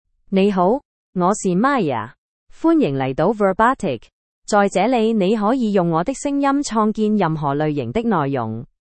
Maya — Female Chinese (Cantonese, Hong Kong) AI Voice | TTS, Voice Cloning & Video | Verbatik AI
Maya is a female AI voice for Chinese (Cantonese, Hong Kong).
Listen to Maya's female Chinese voice.
Female
Maya delivers clear pronunciation with authentic Cantonese, Hong Kong Chinese intonation, making your content sound professionally produced.